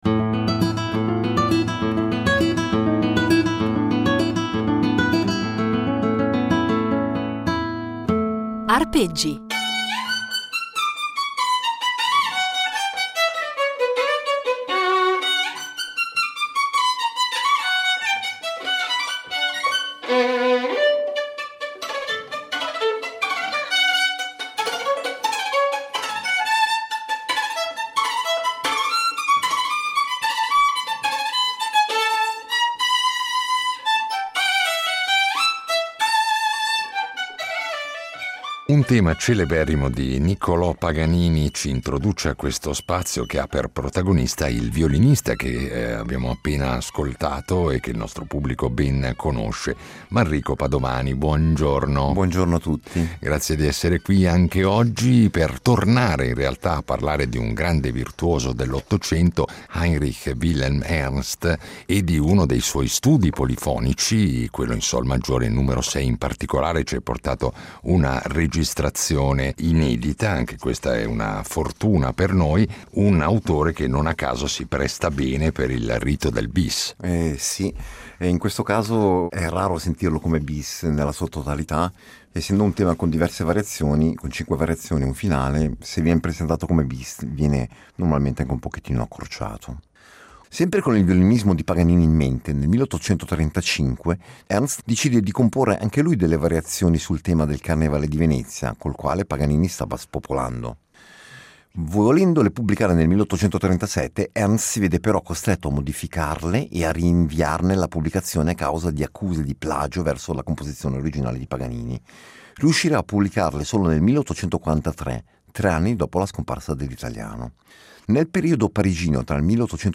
registrazioni, perlopiù inedite, di brani che in molti casi arricchiscono il suo repertorio dedicato al rito concertistico del “Bis”